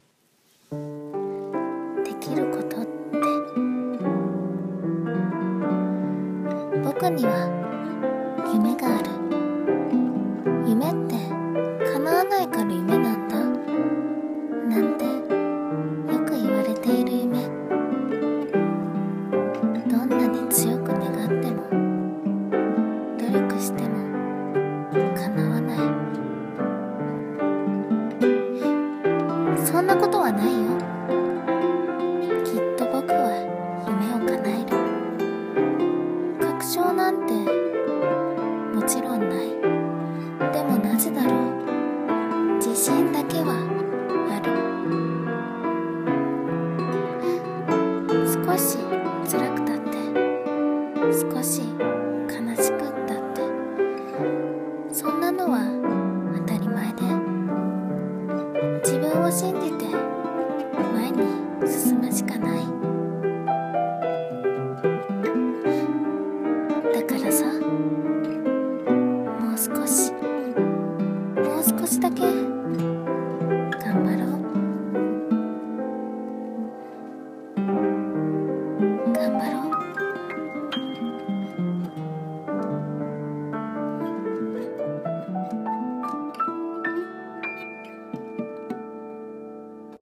[朗読]